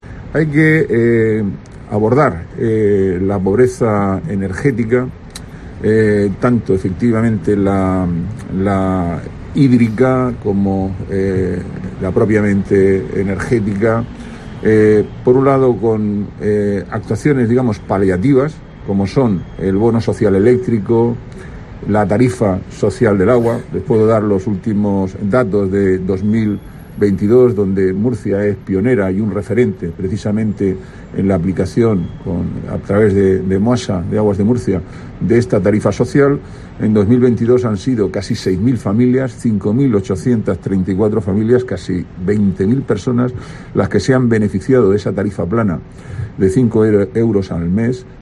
Antonio Navarro, concejal de Planificación Urbanística, Huerta y Medio Ambiente